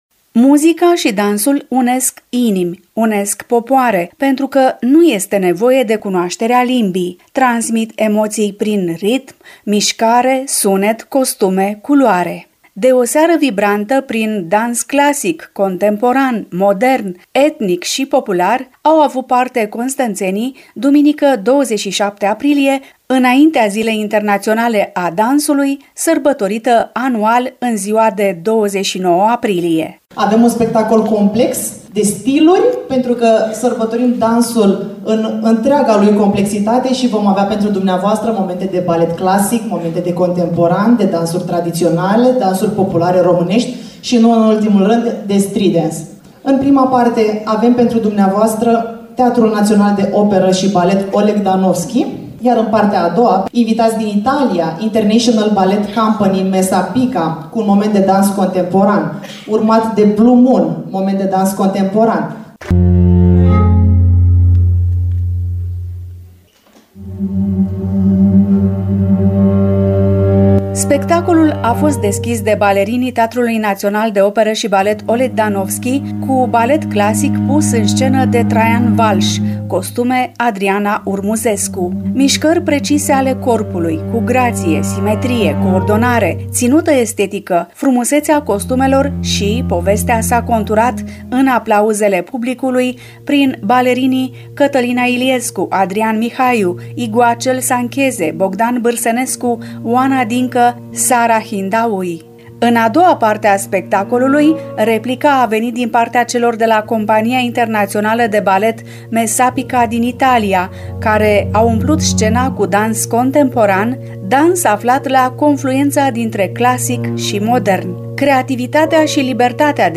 Spectacolul a avut loc  în avans cu două zile, pe scena Casei de Cultură din Constanța.